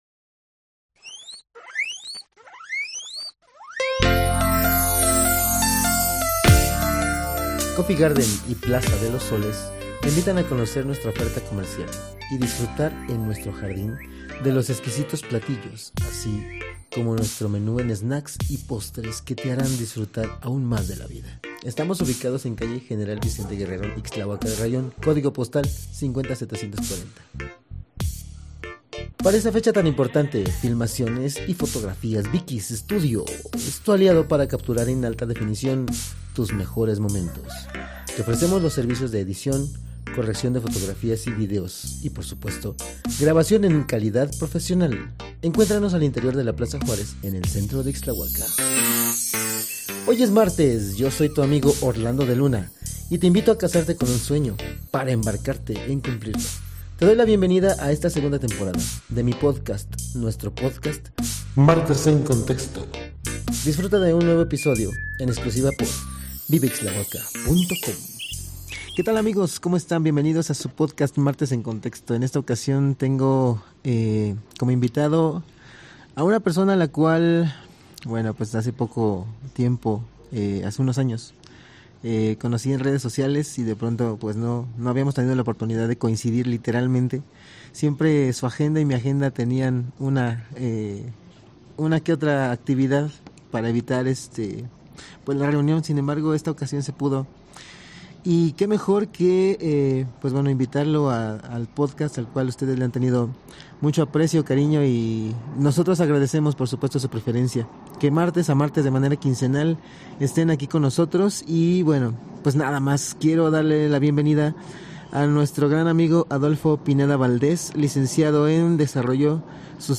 Locación: Cafetería “Coffee Garden".